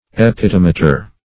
epitomator - definition of epitomator - synonyms, pronunciation, spelling from Free Dictionary Search Result for " epitomator" : The Collaborative International Dictionary of English v.0.48: Epitomator \E*pit"o*ma`tor\, n. [LL.]